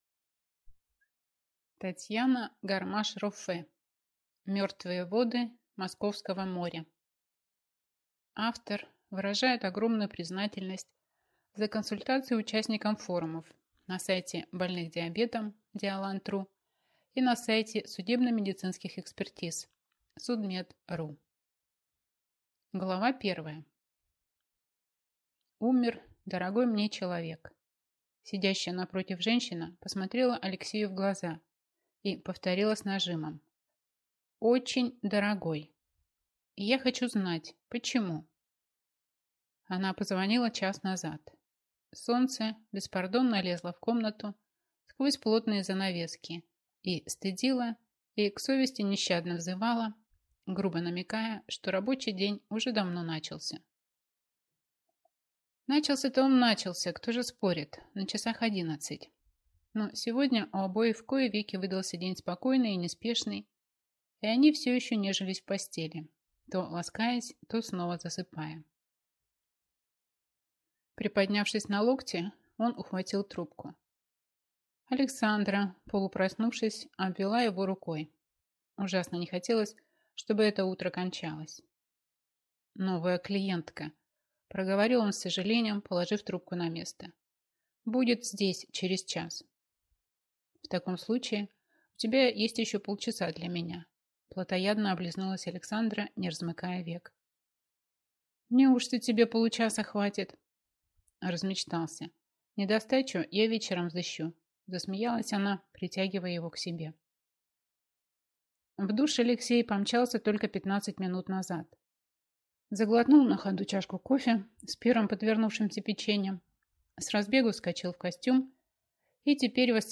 Аудиокнига Мертвые воды Московского моря | Библиотека аудиокниг